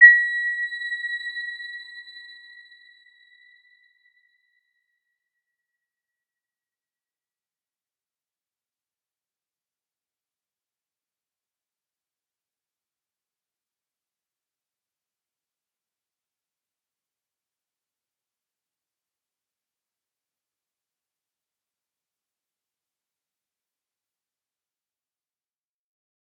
Round-Bell-B6-f.wav